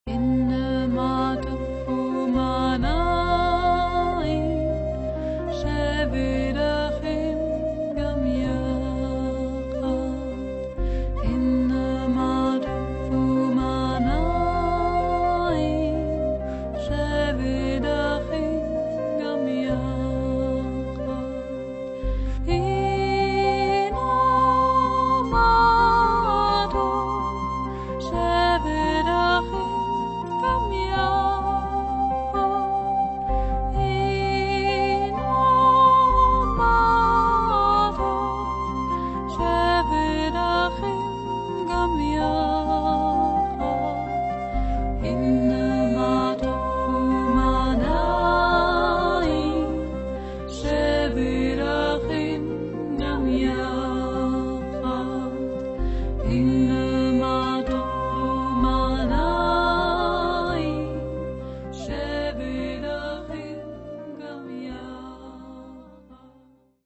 Lieder des Herzens
Die an Engel erinnernde Stimme